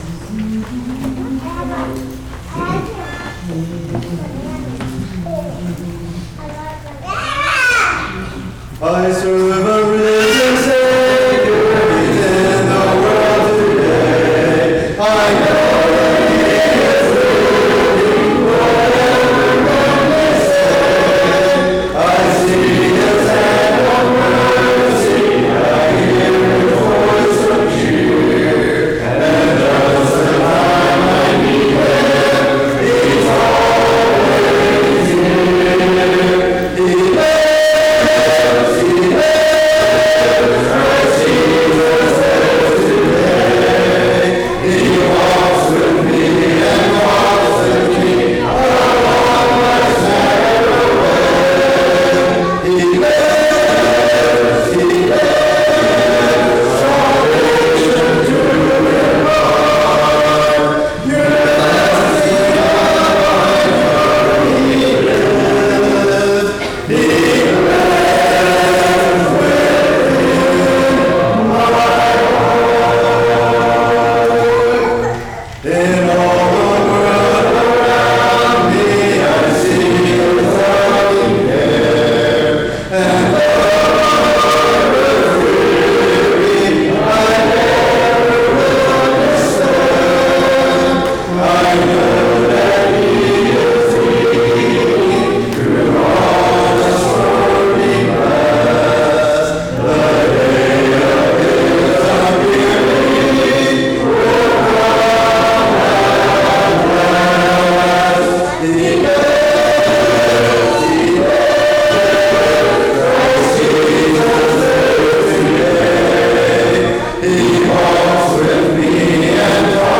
Hymn Sing